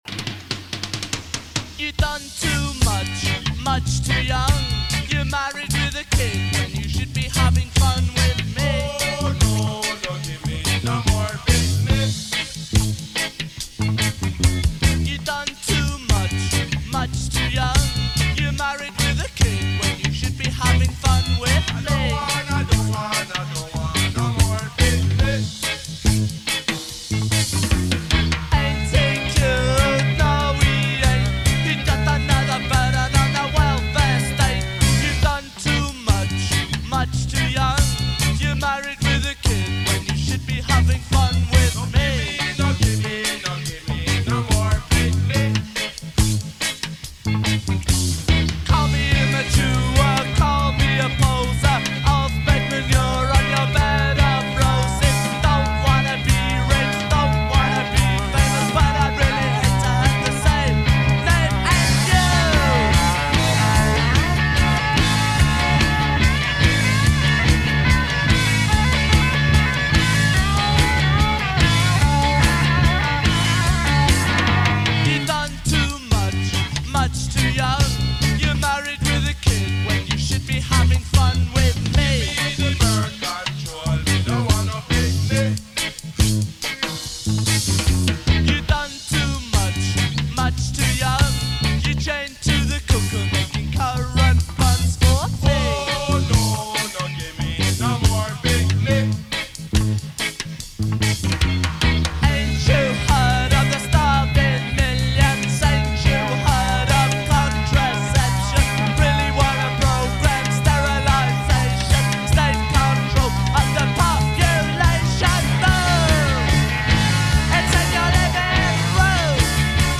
In session
deeply influenced by Jamaican Ska and Rocksteady
keyboards
guitars
horns